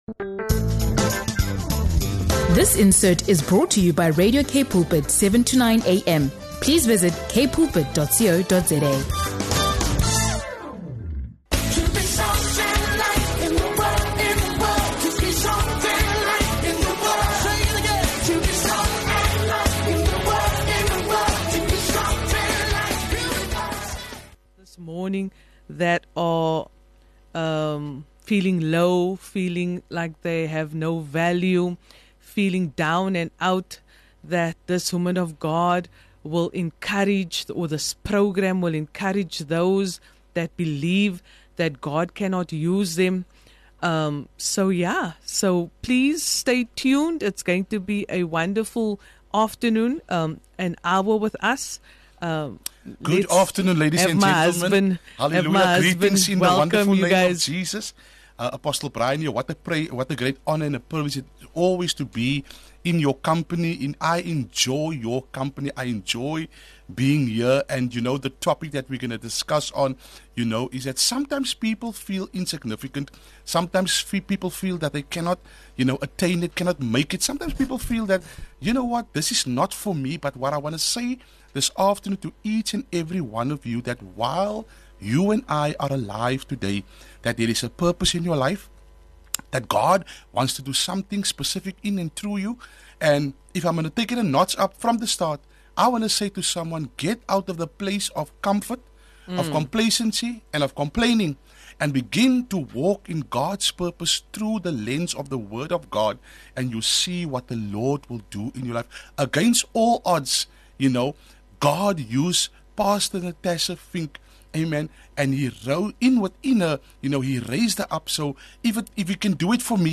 Don’t miss this inspiring conversation that will ignite purpose and passion in your own journey.